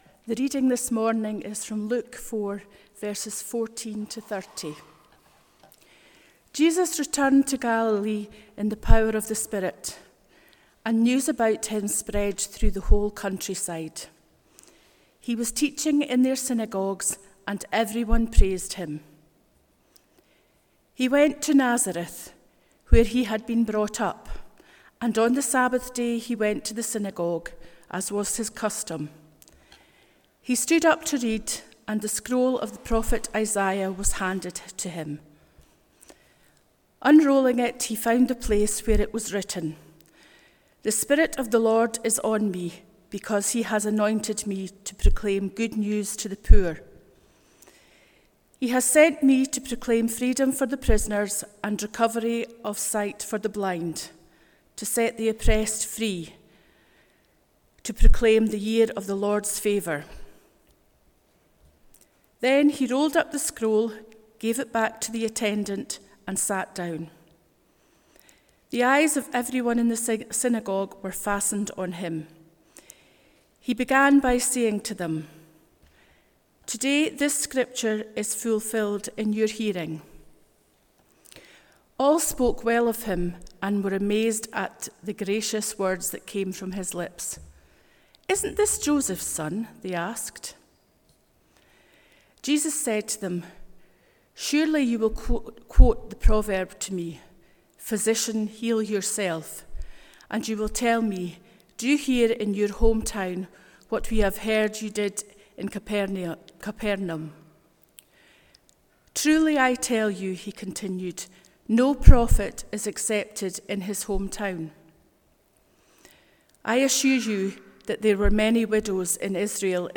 Passage: Luke 4:14-30 Service Type: Sunday Morning « Is loving our neighbour as ourself not enough?